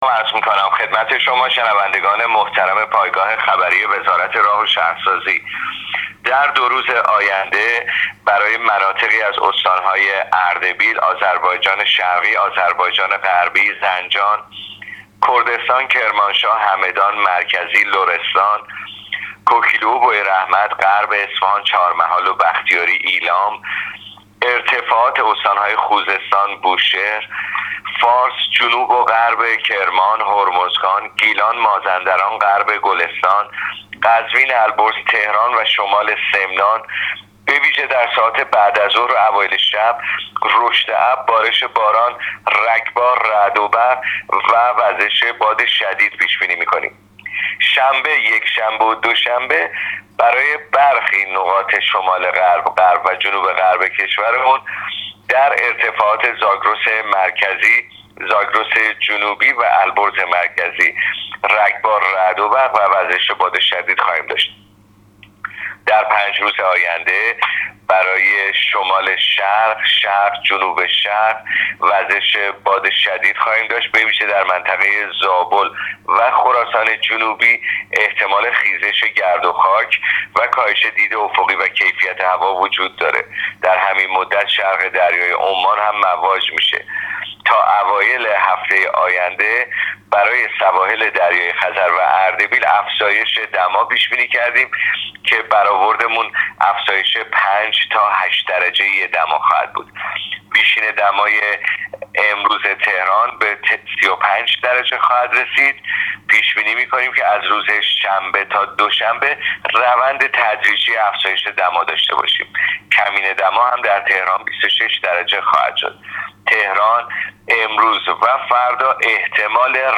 کارشناس سازمان هواشناسی کشور در گفت‌وگو با رادیو اینترنتی وزارت راه و شهرسازی، آخرین وضعیت آب‌و‌هوای کشور را تشریح کرد.
گزارش رادیو اینترنتی از آخرین وضعیت آب‌‌و‌‌‌هوای چهاردهم مرداد: